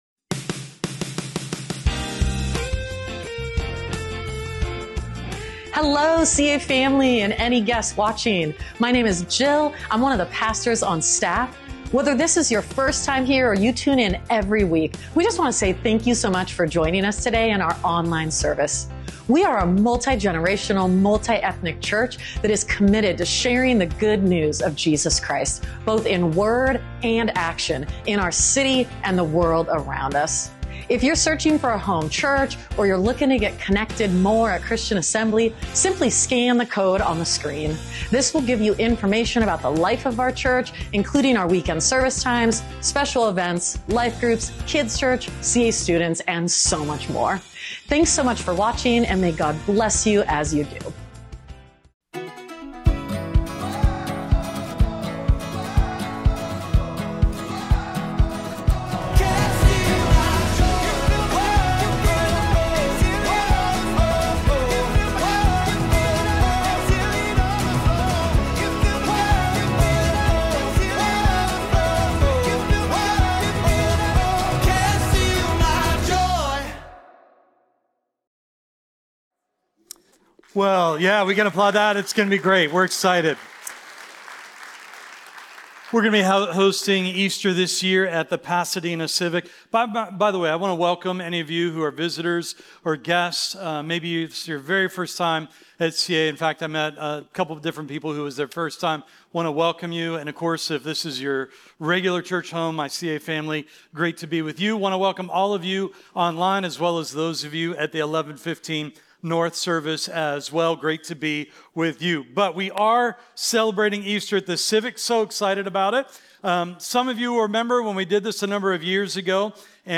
Weekend-Service-3.9.25.mp3